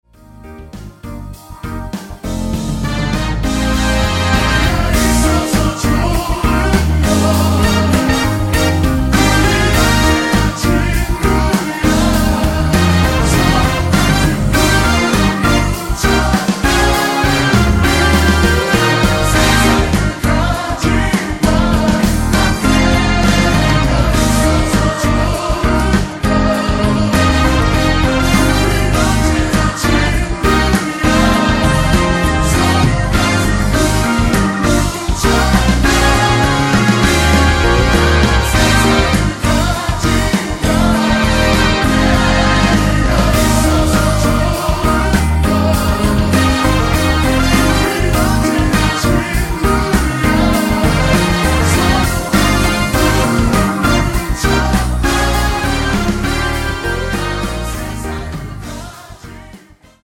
원곡 3분 지나서 부터 나오는 코러스 포함된 MR입니다.(미리듣기 확인)
원키에서(-2)내린 코러스 포함된 MR입니다.
Eb
앞부분30초, 뒷부분30초씩 편집해서 올려 드리고 있습니다.